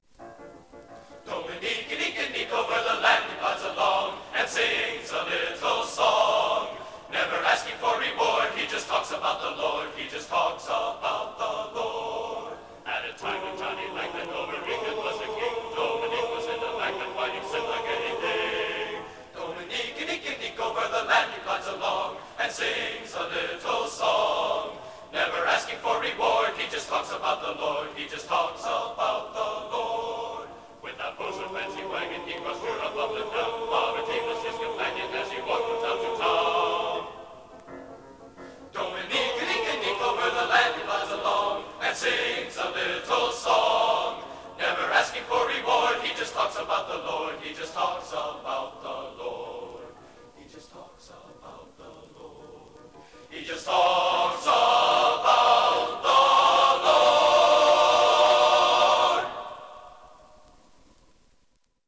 Genre: | Type: Studio Recording